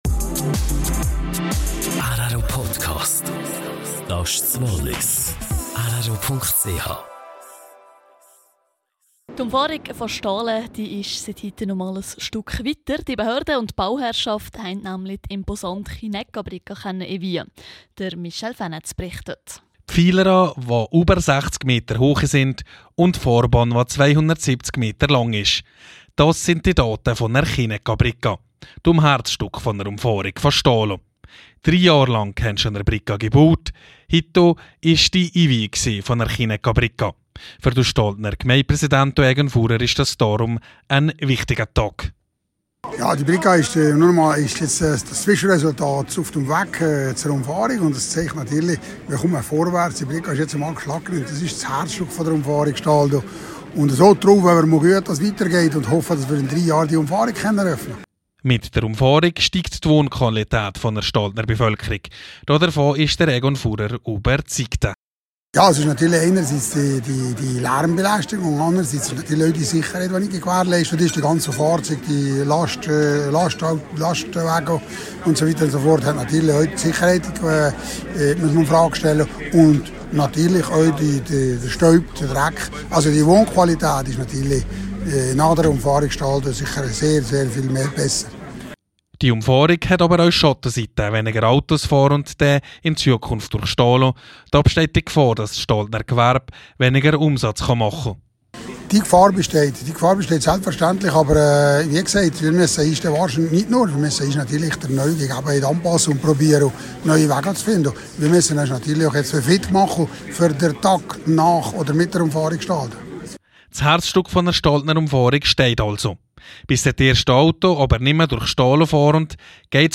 Ein Bericht